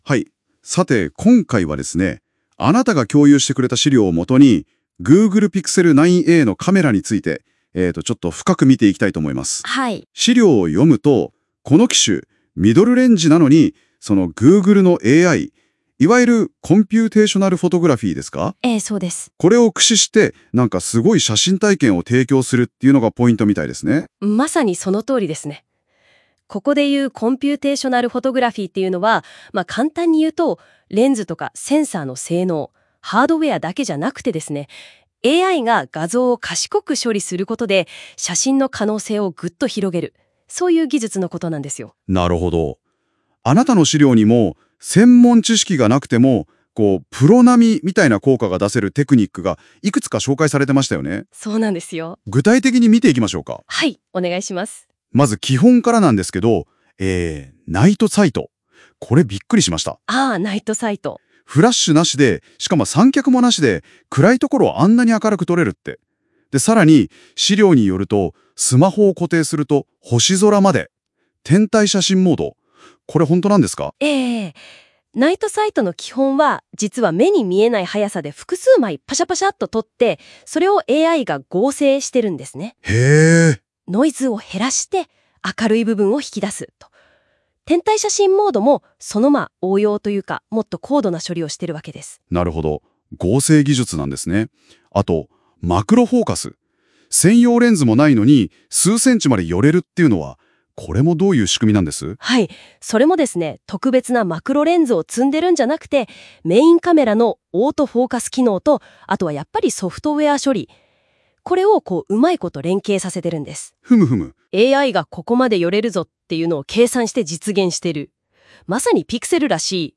なお、本記事の内容＋引用元の情報を翻訳して詳しく会話形式で伝えているので記事本文の要約だけではありませんので注意してください。